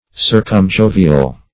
Search Result for " circumjovial" : The Collaborative International Dictionary of English v.0.48: Circumjovial \Cir`cum*jo"vi*al\, n. [Pref. circum- + L. Jupiter, gen. Jovis, Jove.] One of the moons or satellites of the planet Jupiter.